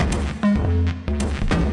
鼓点
描述：在低音鼓或低沉的汤姆上打出简单、有规律、稳定的节拍
标签： 低音 汤姆 击败